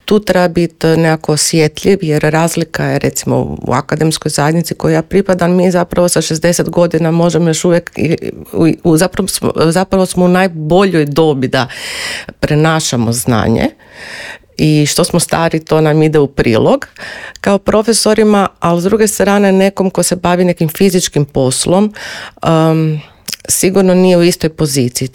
Gostujući u Intervjuu Media servisa objasnila je da je gerontologija znanstvena disciplina koja u svom fokusu ima osobe starije životne dobi.